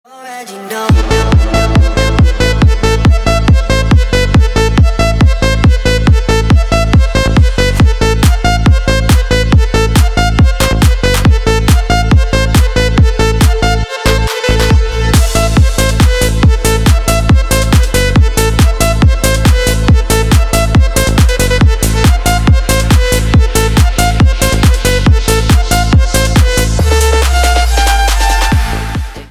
клубные рингтоны 2024
громкие рингтоны 2024